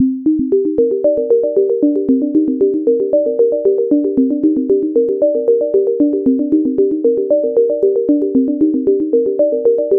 I finally got around to making a clock-synced delay line that works with .